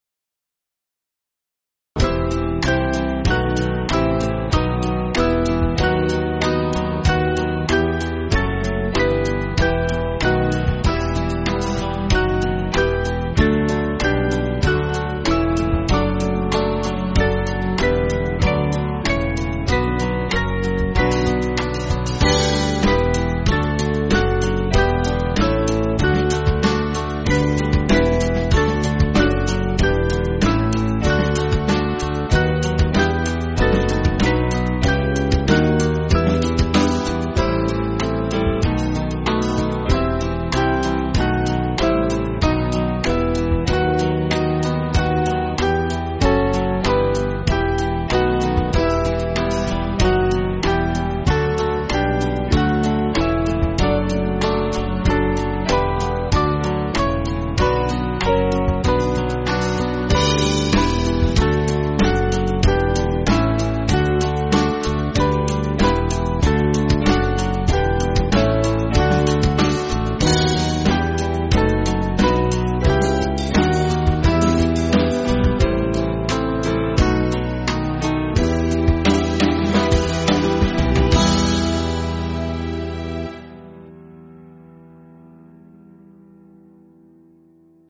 Small Band
(CM)   2/Em